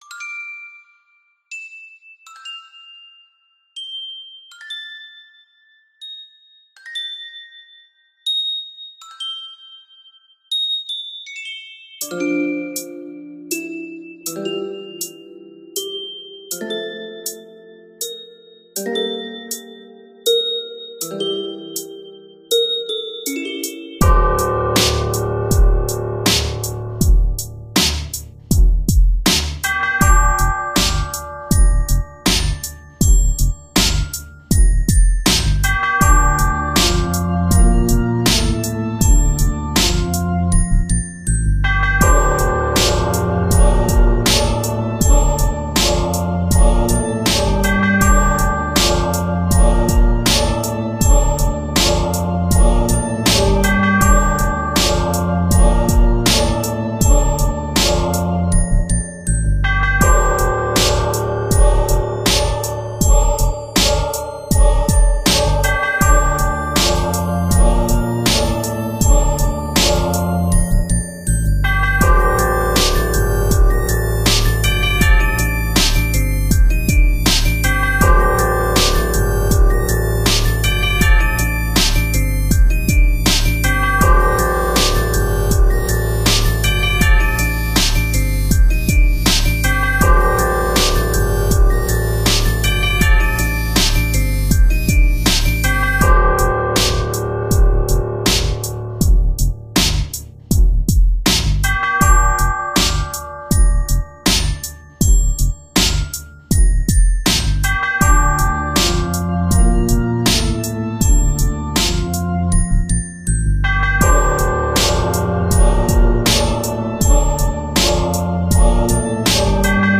- Bonus track, Hip Hop
The link above are in low quality, if you want a better sound, you must download the FLAC ones from OGA.